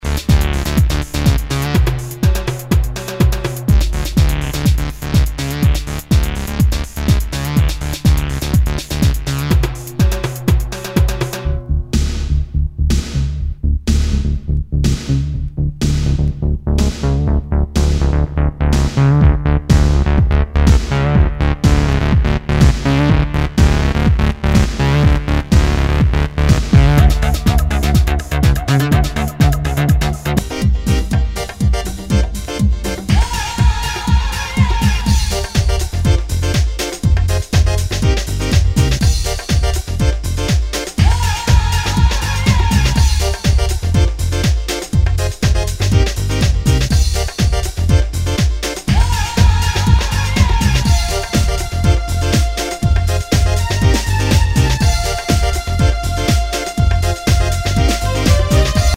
HOUSE/TECHNO/ELECTRO
ナイス！テクノ・クラシック！